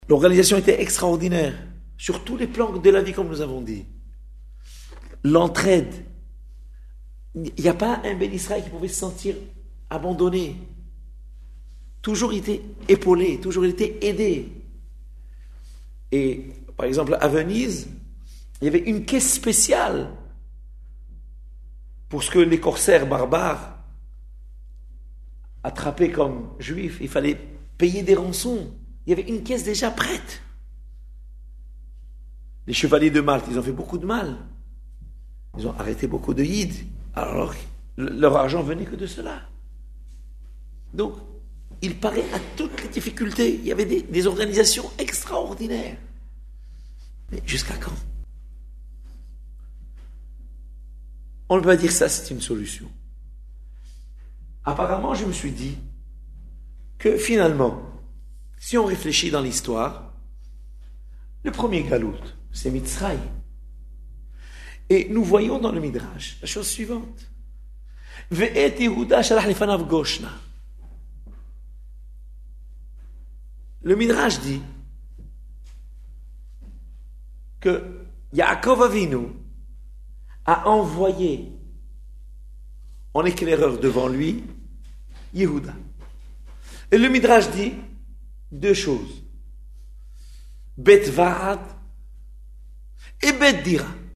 Exposé magistral
à la Shoule de la Adass Yereïm, rue Cadet à Paris